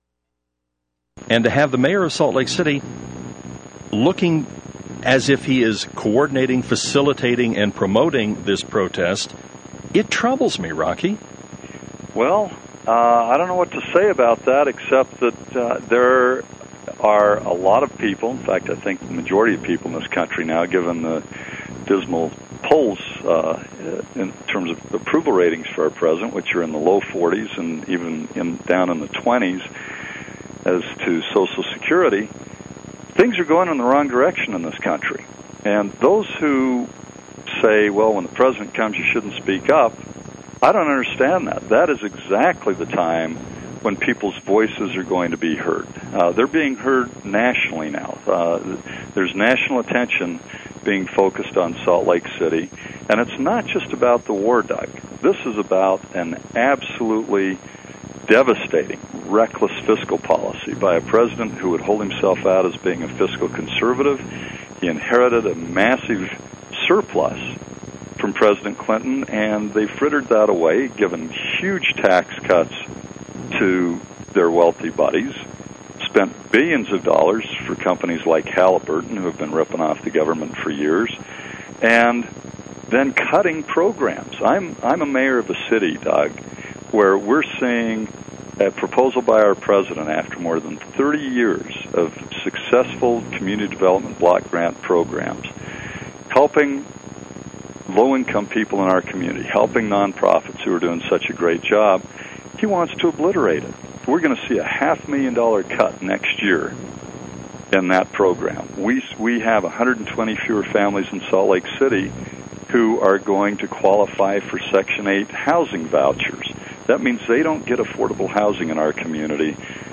Rocky supports the peace protest in Salt Lake City and is one of the main organizing forces behind it which has some right wingers up in arms. Anderson came armed with facts as he debated the right wing talk show host.